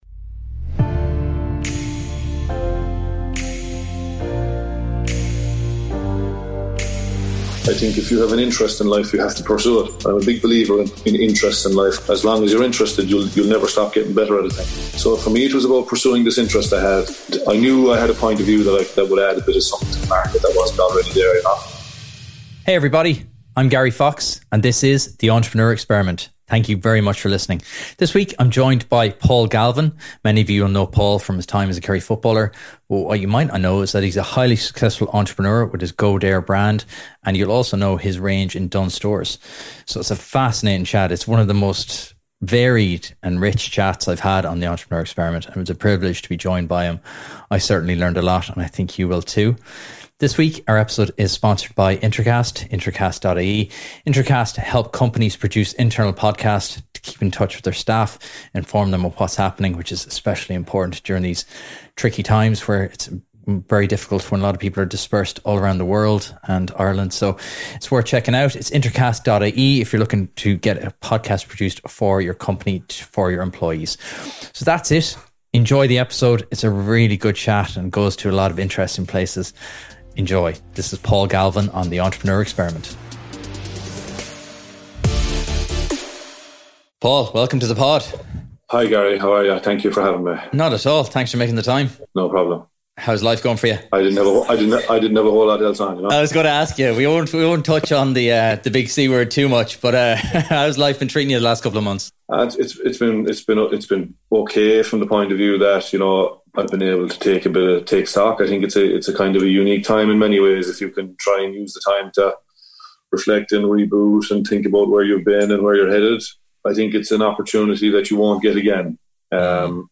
I'm joined this week by Paul Galvin for one of the most varied and inspiring chats I've ever had on the podcast.